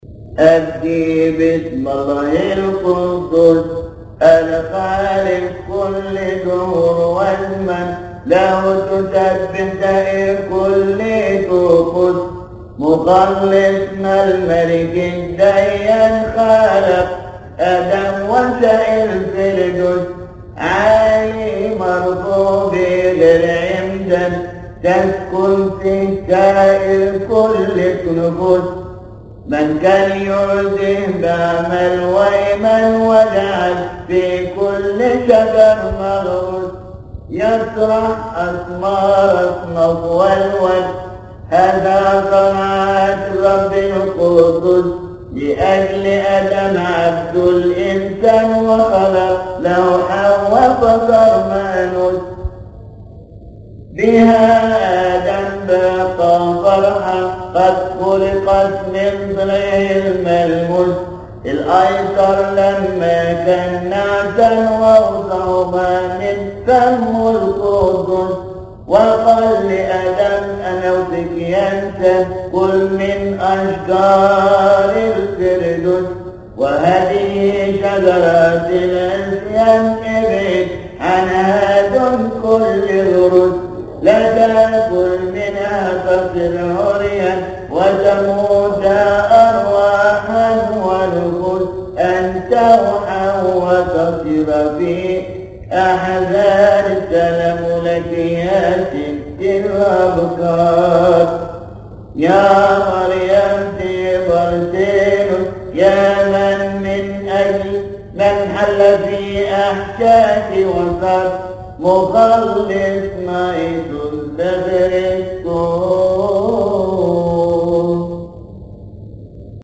المرتل
يصلي في تسبحة عشية أحاد شهر كيهك